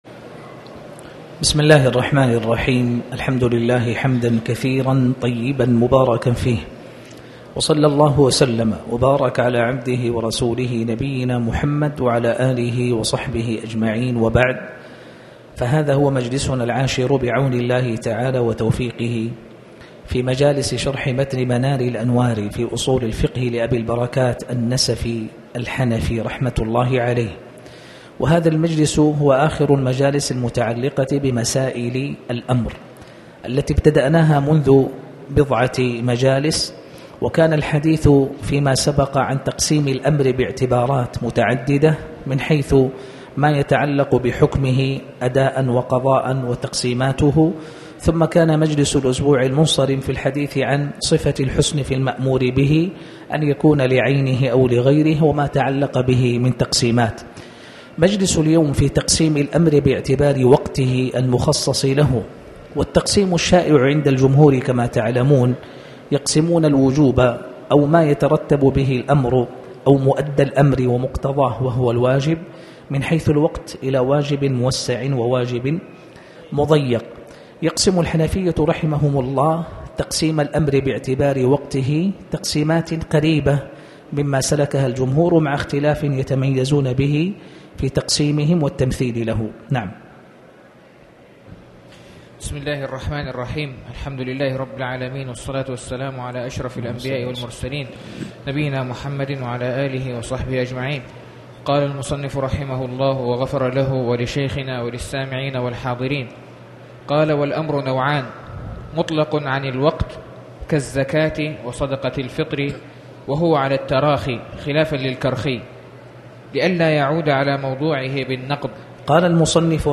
تاريخ النشر ١١ ربيع الأول ١٤٣٩ هـ المكان: المسجد الحرام الشيخ